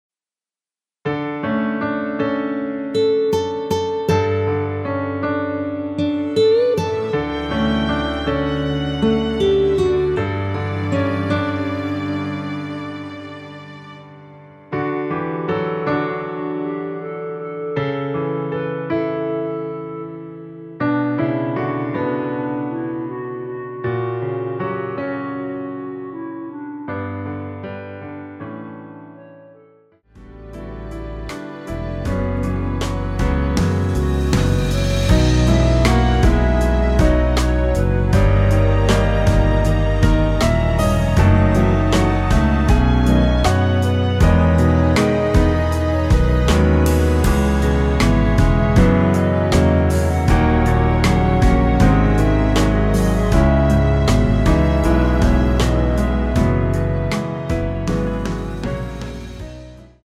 원키에서(-6)내린 멜로디 포함된 MR 입니다.(미리듣기 참조)
앞부분30초, 뒷부분30초씩 편집해서 올려 드리고 있습니다.
중간에 음이 끈어지고 다시 나오는 이유는